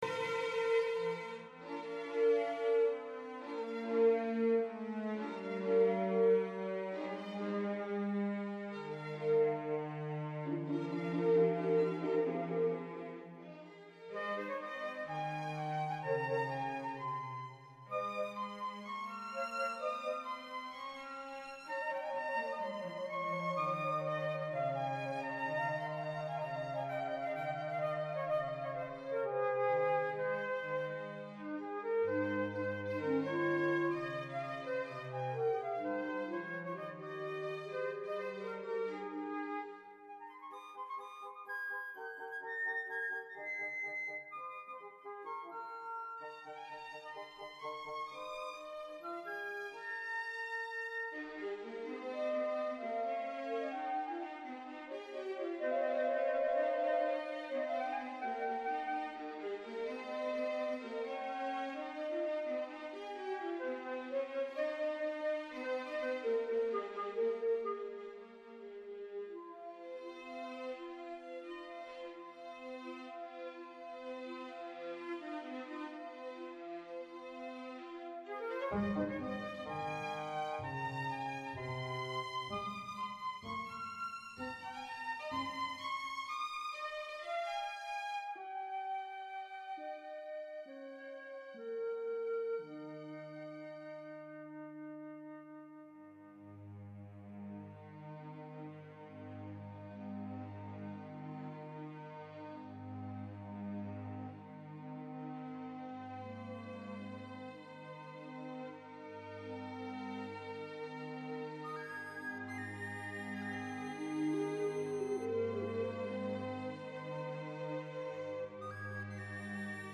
MP3 clip from this title's soundtrack